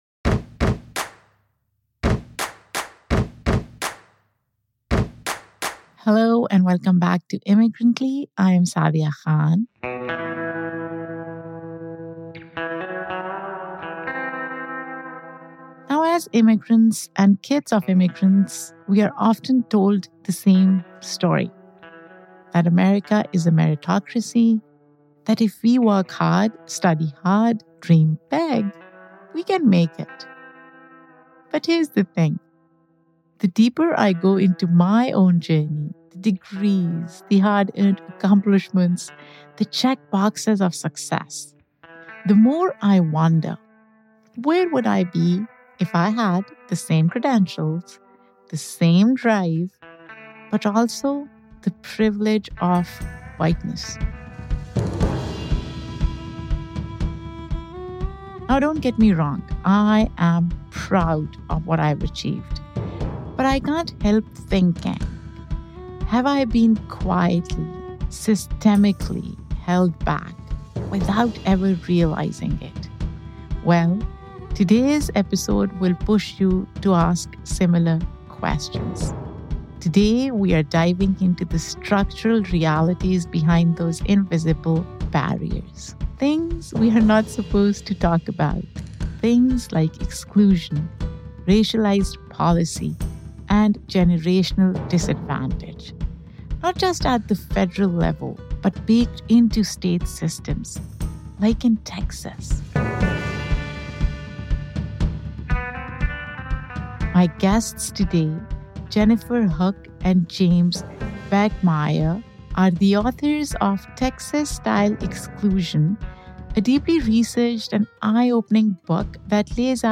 This conversation reframes the myth of meritocracy and compels us to confront the uncomfortable truth: success in America has always depended on who you are, where you’re from, and what you are allowed to access.